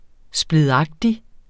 Udtale [ sbliðˈɑgdi ]